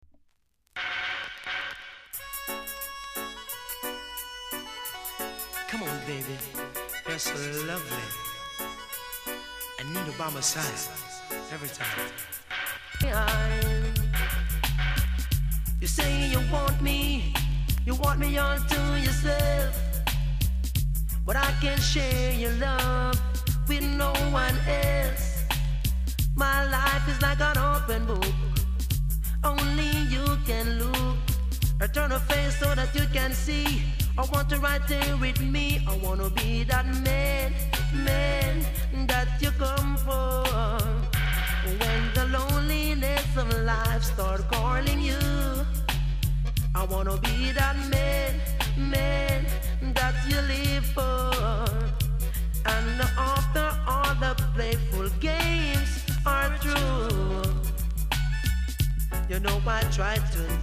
※小さなチリノイズが少しあります。